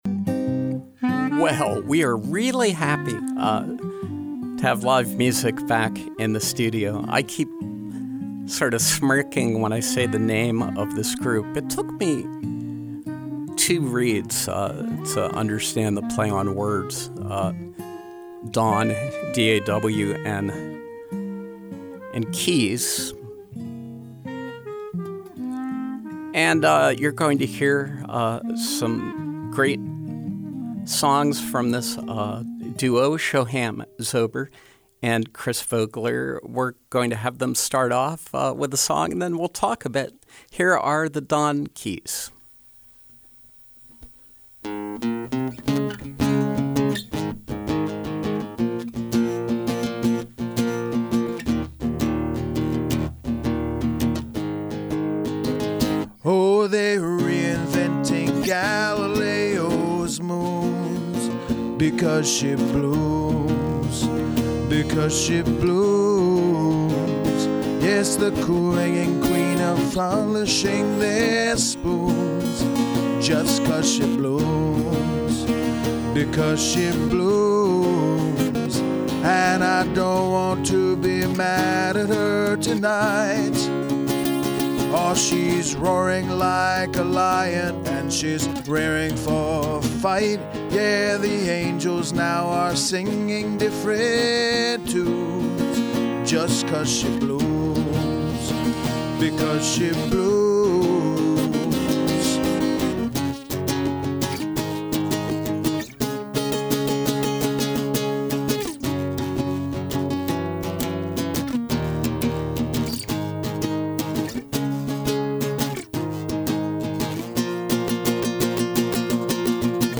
Live acoustic music from duo
Great songs, great banter.